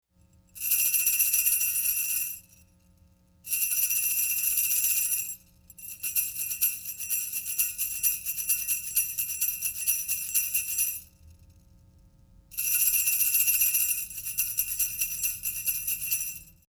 Iat Bell Cacho Shaker - Peru (0797B)
It is 10" long (with velcro ends for ease of attachment) and contains 6 pair of small metal jingles.
This instrument produces a sound similar to sleigh bells but quieter and with less movement sound.